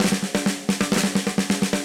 Index of /musicradar/80s-heat-samples/130bpm
AM_MiliSnareB_130-03.wav